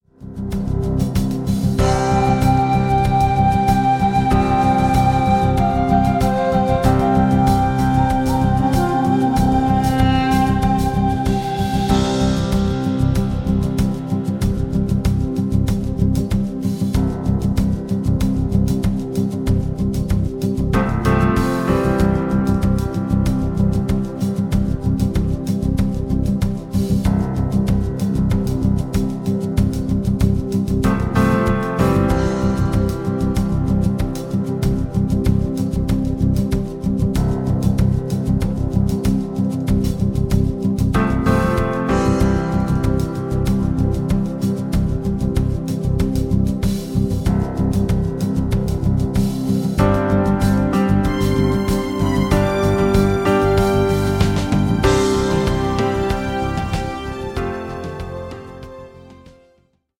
Playback ohne Backings